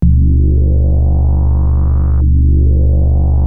JUP 8 G2 9.wav